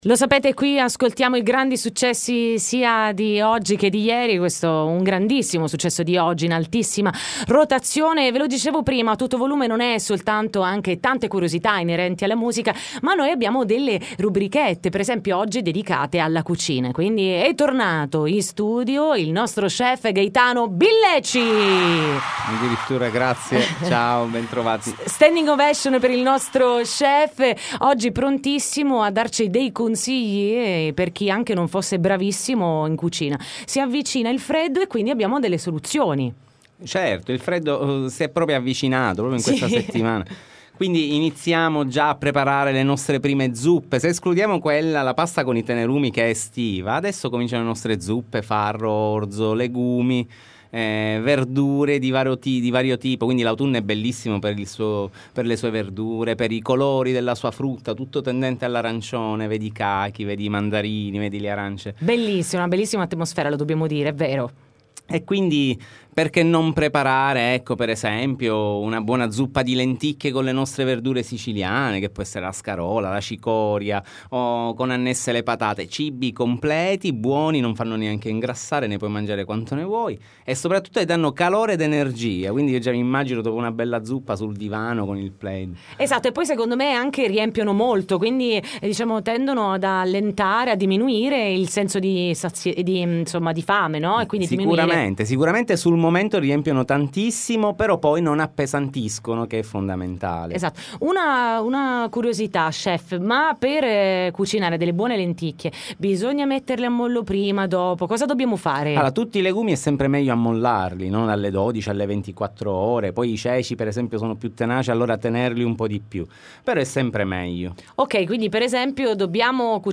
A Tutto Volume: intervista Adragna Petfood